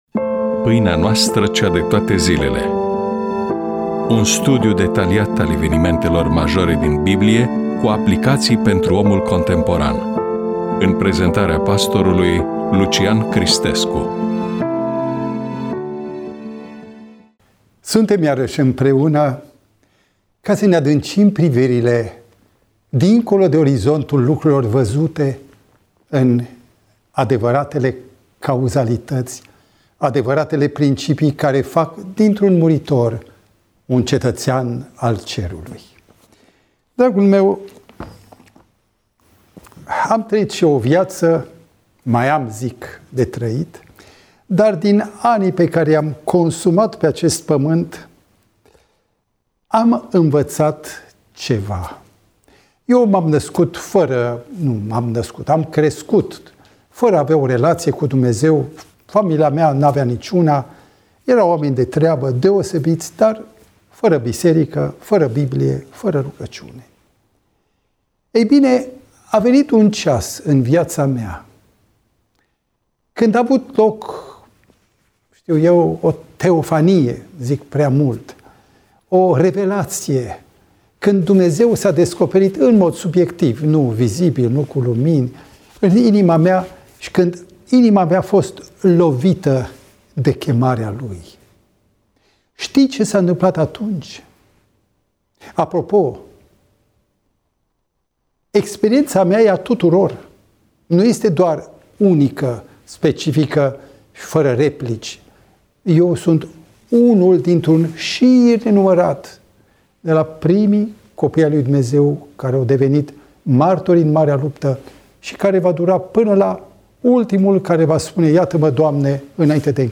EMISIUNEA: Predică DATA INREGISTRARII: 17.04.2026 VIZUALIZARI: 3